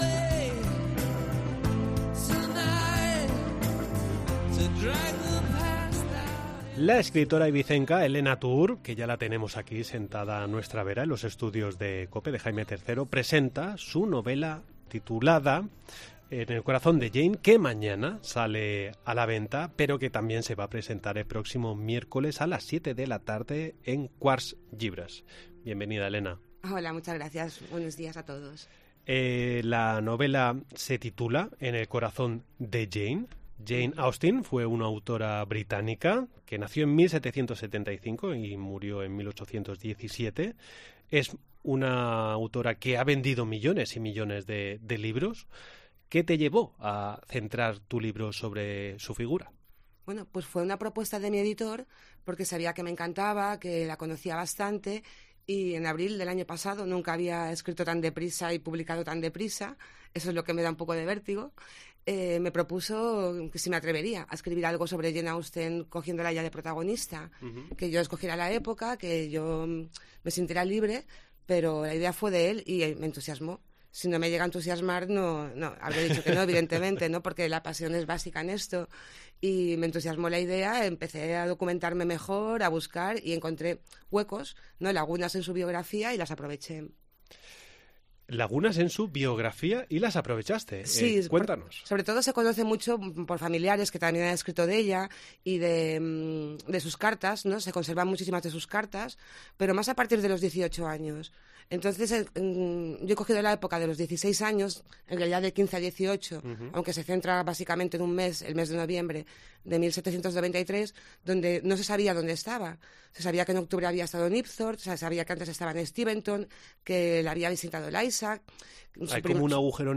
Charlamos con ella en los estudios de Cope